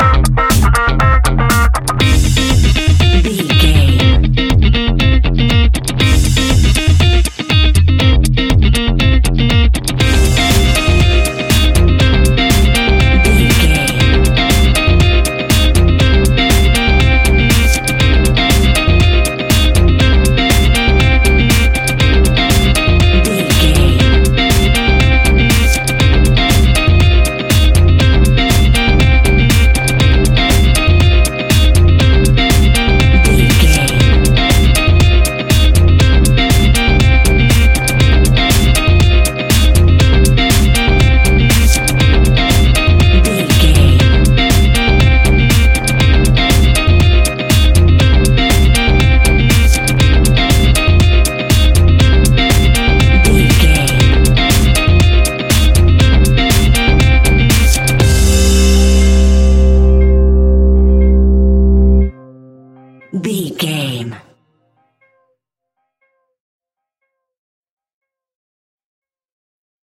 Aeolian/Minor
energetic
repetitive
electric guitar
bass guitar
synthesiser
drum machine
piano
funky house
electronic funk
upbeat
synth leads
Synth Pads
synth bass